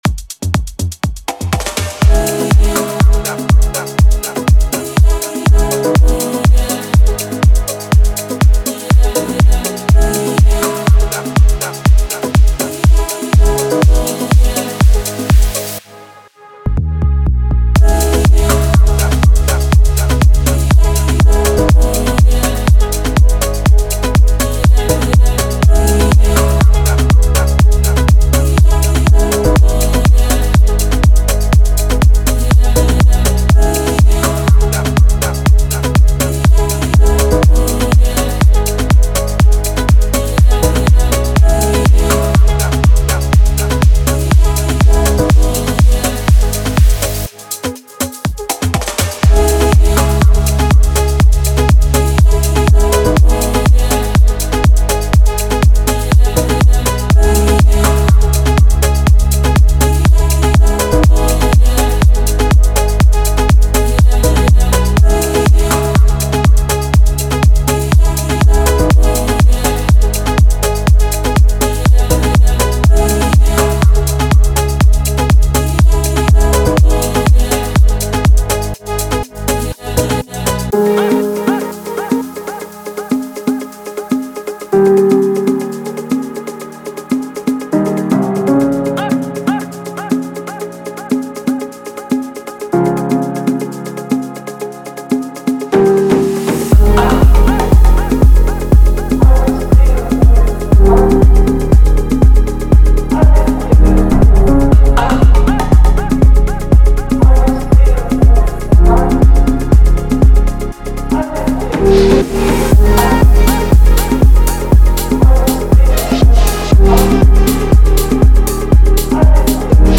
Type: Serum Midi Samples
House Phonk house Tropical House
• 36 Bass Loops
• 60 Drum Loops
• 69 Synth Loops
• 8 Vocal Samples